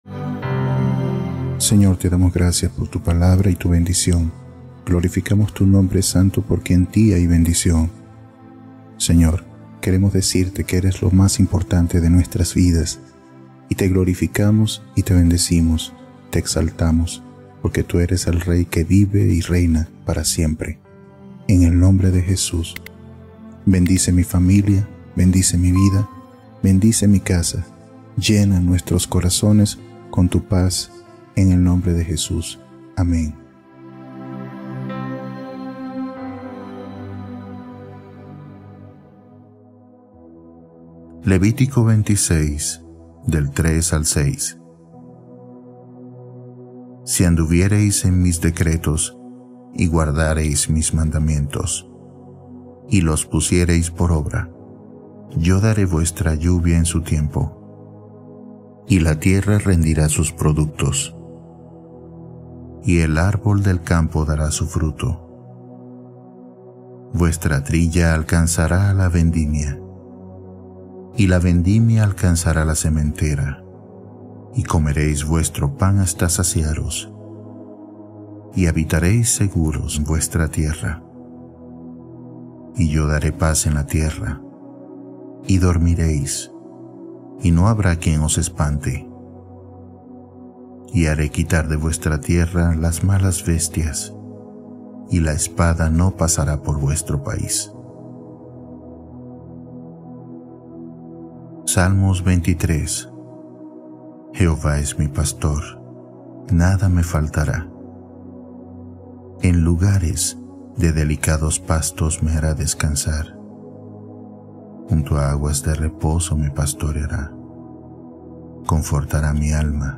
Versículos hermosos para dormir | Biblia hablada